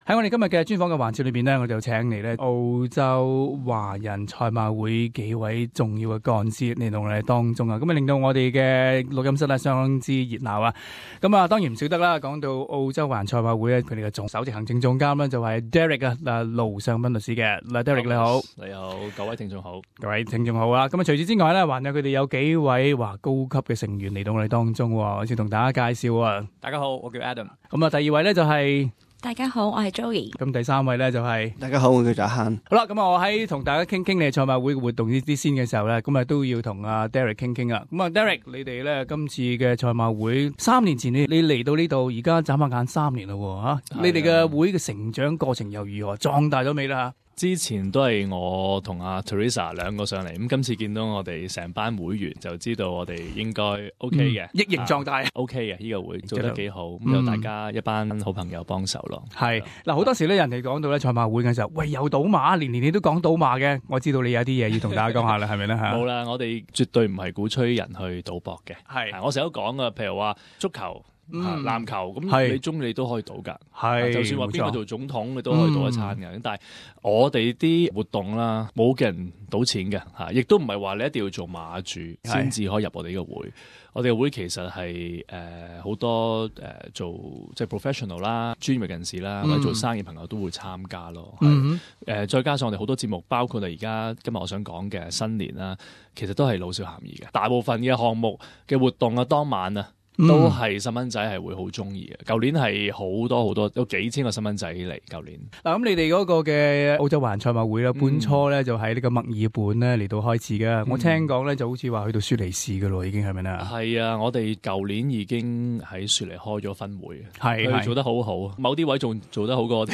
【社團專訪】澳洲華人賽馬會新春嘉年華活動